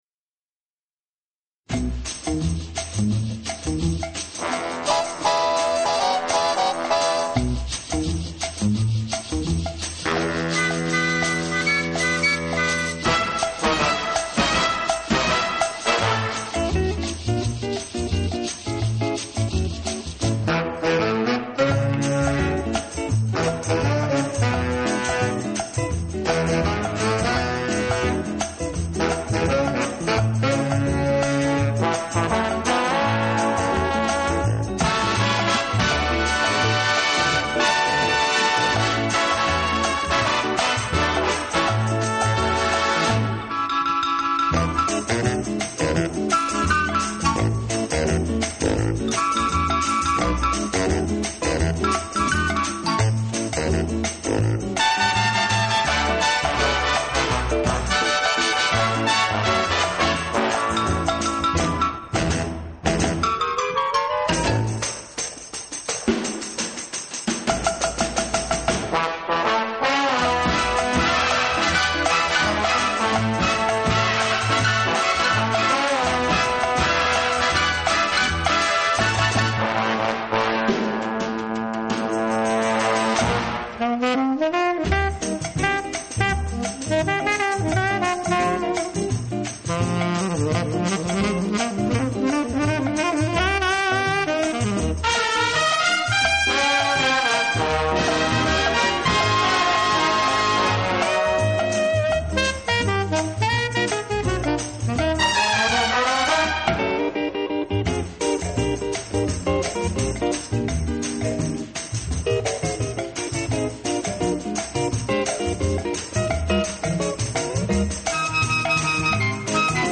【轻音爵士】